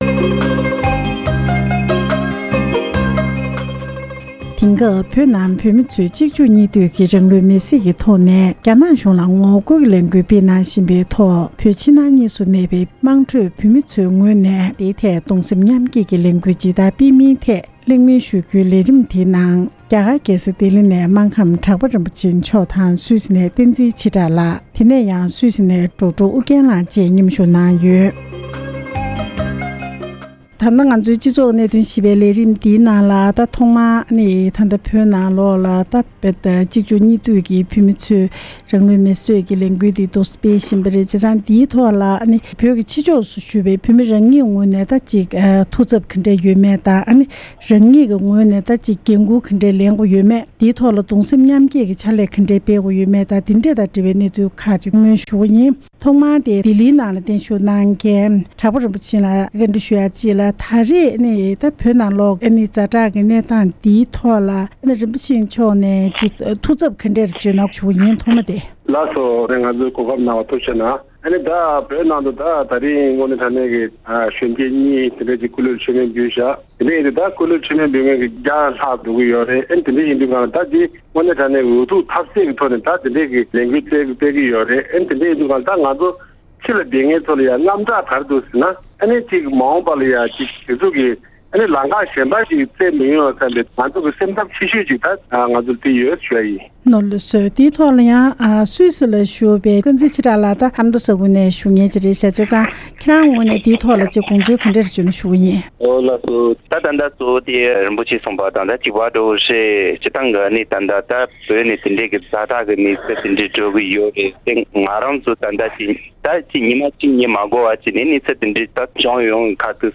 བཙན་བྱོལ་དུ་ཡོད་པའི་བོད་ཚོས་གདུང་སེམས་མཉམ་བསྐྱེད་ཀྱི་ལས་འགུལ་གང་འདྲ་སྤེལ་དགོས་ཡོད་མིན་ཐད་བོད་མི་ཁག་ལ་བཅར་འདྲི་ཞུས་པ།